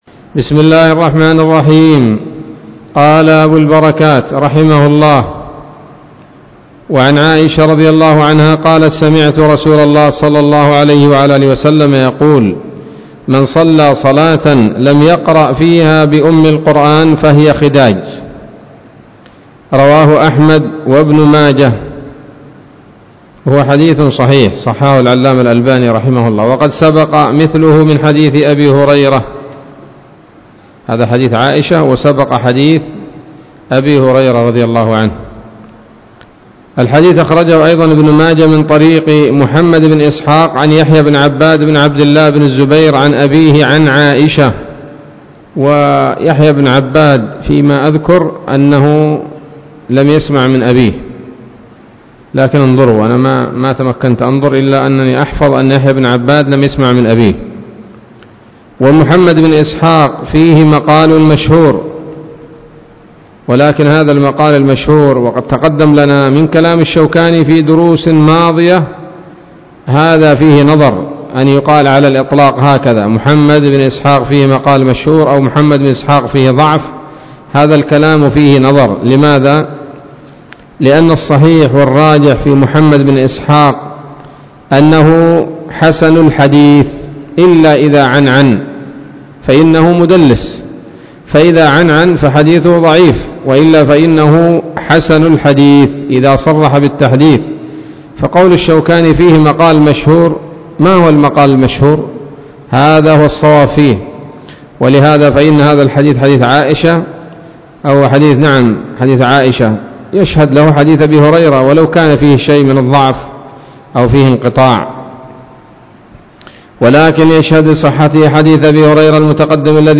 الدرس الحادي والثلاثون من أبواب صفة الصلاة من نيل الأوطار